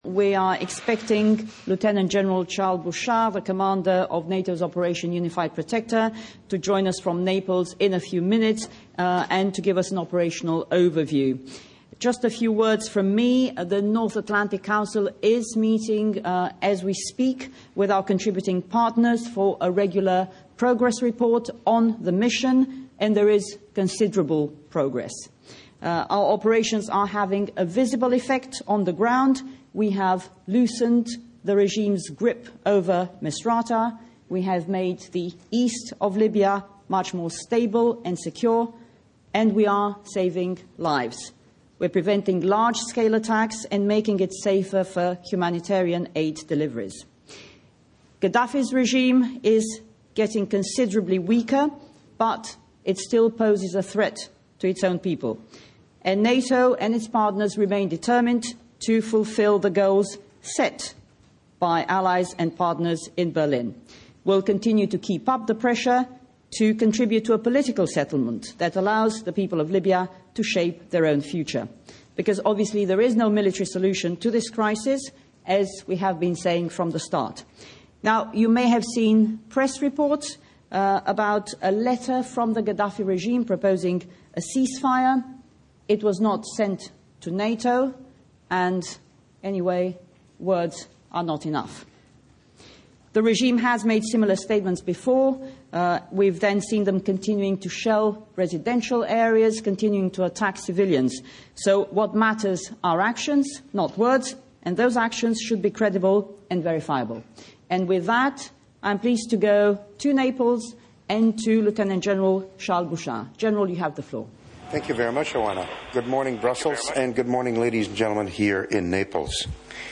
Press briefing on Libya by the NATO Spokesperson, Oana Lungescu and Lieutenant General Charles Bouchard, Commander of the NATO military operation Unified Protector
On 27 May 2011, Oana Lungescu, the NATO Spokesperson, briefed the press on events concerning Libya. She was joined via videoconference from Naples by Lieutenant General Charles Bouchard, Commander of the NATO military operation “Unified Protector”, who briefed on NATO’s operation in Libya in support of UNSCR 1973.